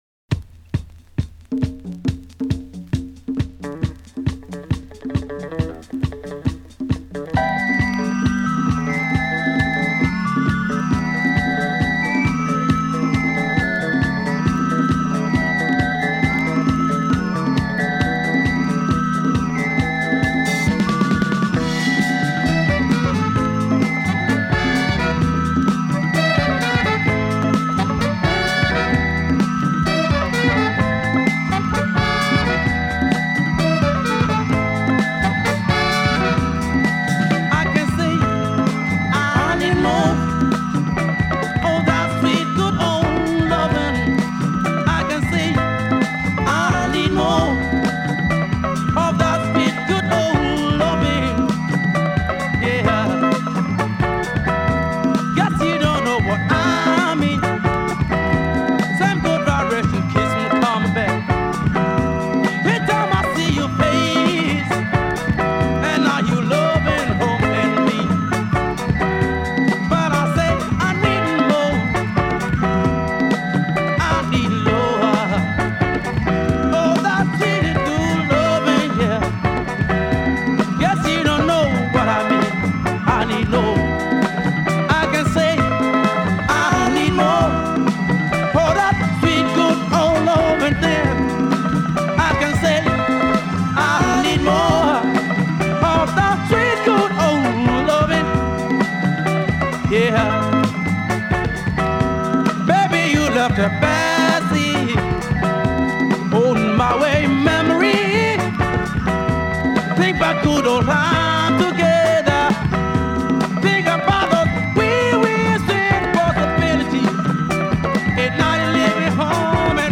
drums
keyboards
guitar